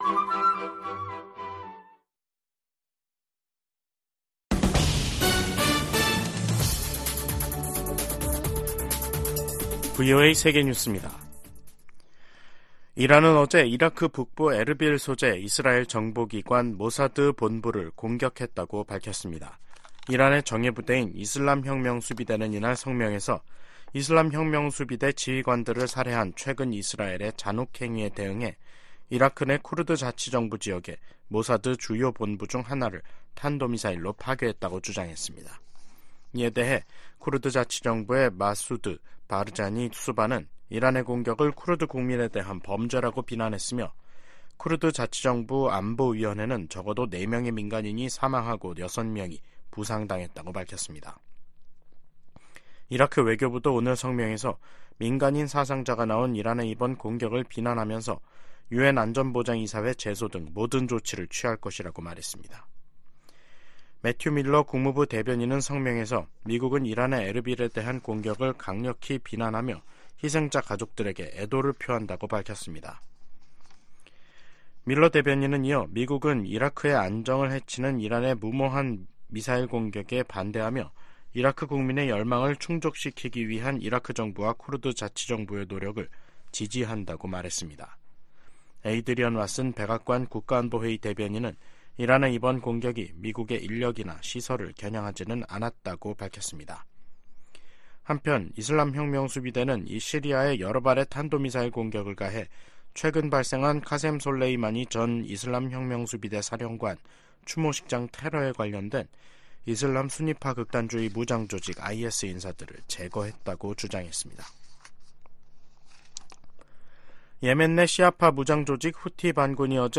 VOA 한국어 간판 뉴스 프로그램 '뉴스 투데이', 2024년 1월 16일 2부 방송입니다. 김정은 북한 국무위원장은 한국을 '제1의 적대국'으로 명기하는 헌법개정 의지를 분명히 했습니다.